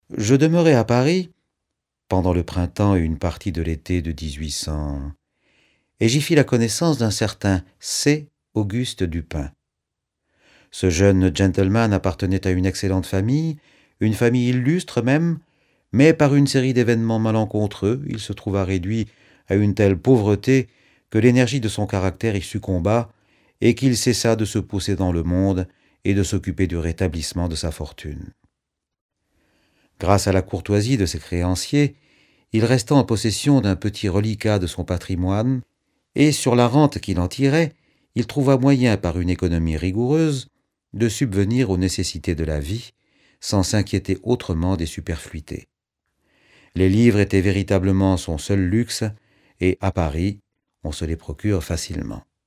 je découvre un extrait - Double assassinat dans la rue Morgue de Edgar Allan Poe